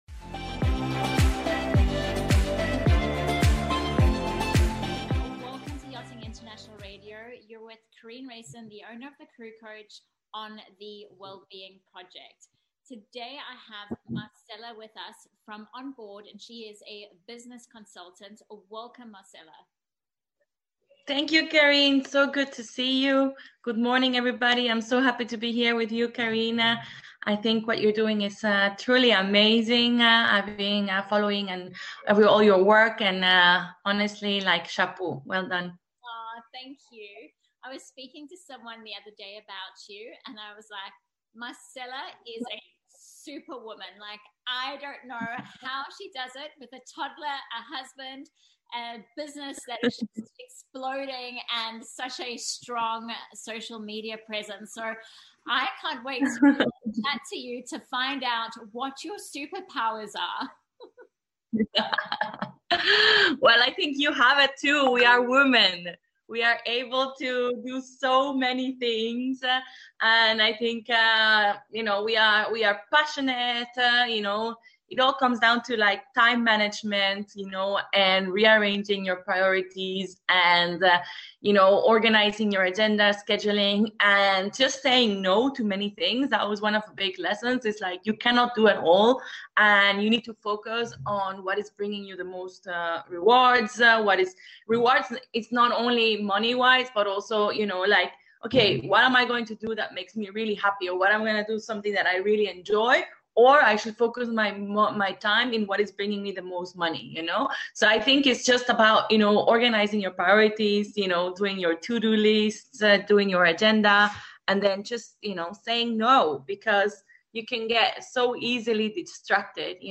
I love hearing how individuals embrace diversity and turn it into success. This interview highlights the importance of having a vision and being open and adaptable in order to make that vision come into a reality.